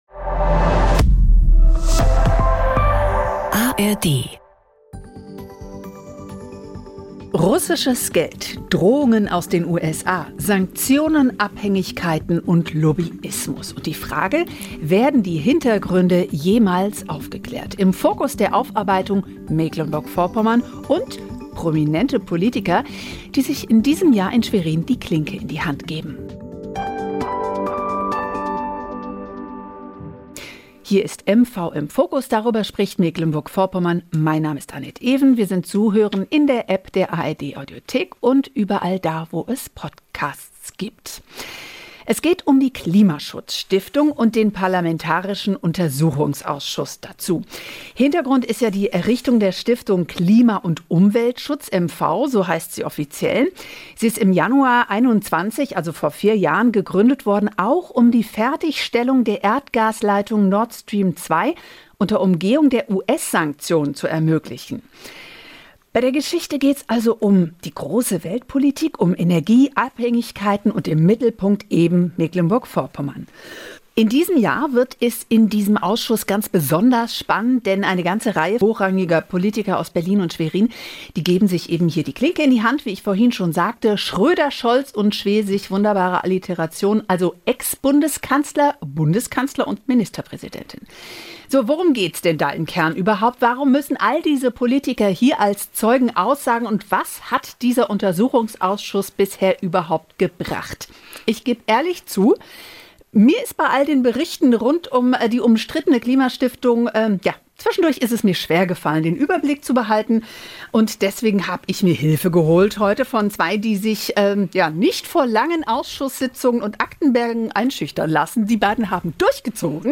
talken dazu wöchentlich im Wechsel mit Reporterinnen und Reportern aus den NDR MV Regionalstudios oder mit spannenden Gästen.